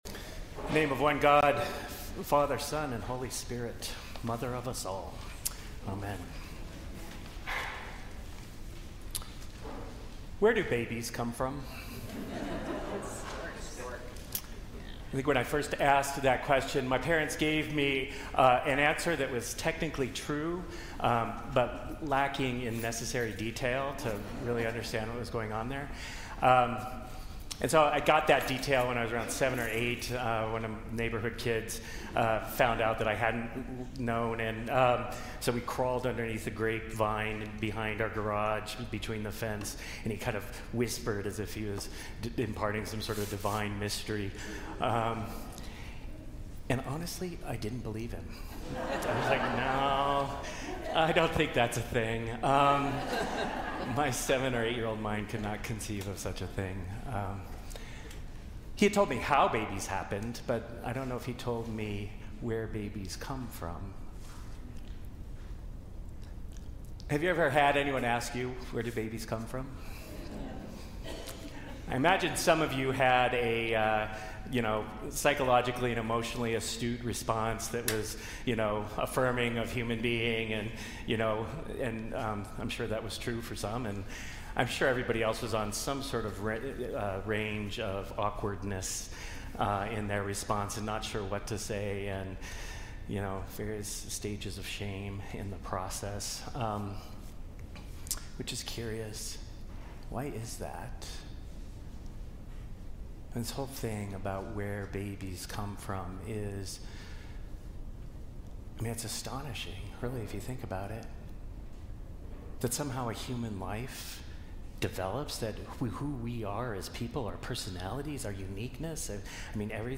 Sermons | Grace Episcopal Church